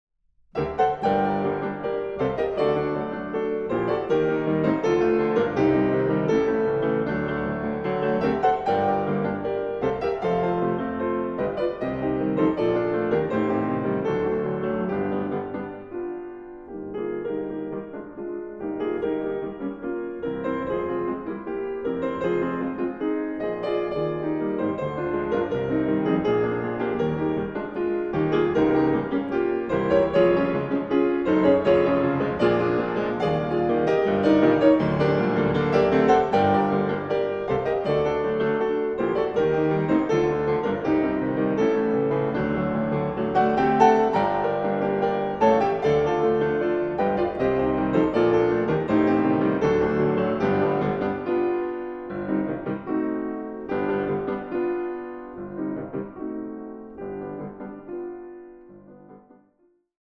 Piano
Ballade. Allegro energico